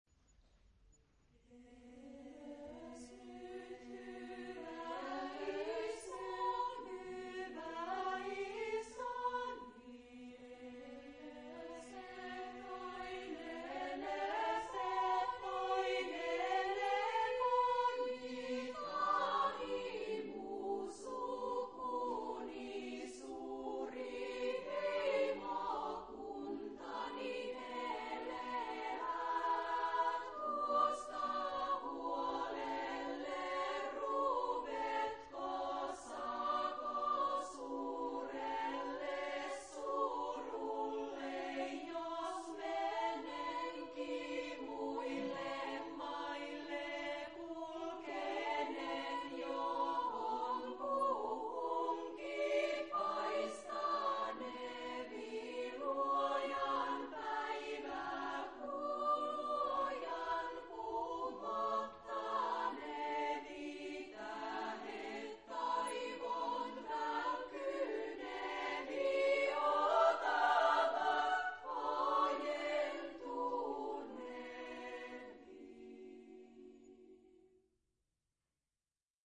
Epoque: 20th century
Genre-Style-Form: Popular
Mood of the piece: rhythmic
Type of Choir: SSAA  (4 women voices )